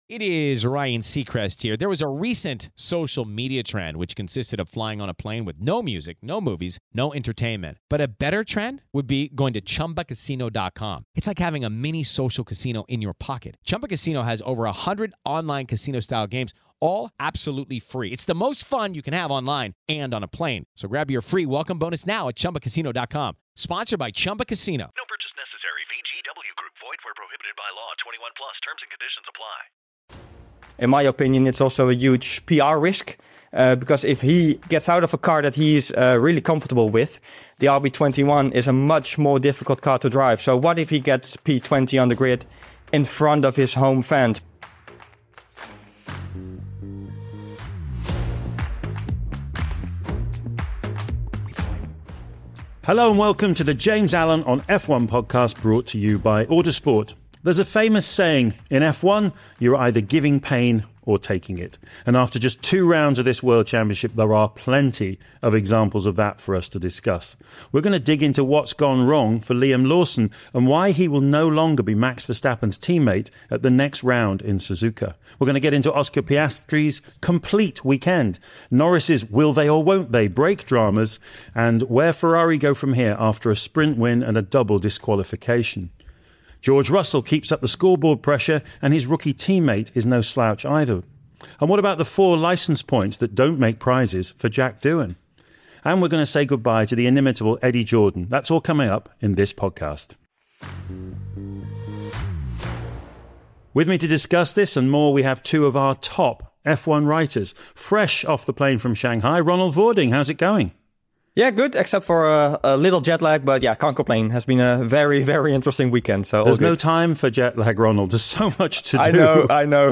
A Motorsport Studios production for Autosport